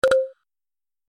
دانلود آهنگ کلیک 14 از افکت صوتی اشیاء
دانلود صدای کلیک 14 از ساعد نیوز با لینک مستقیم و کیفیت بالا
جلوه های صوتی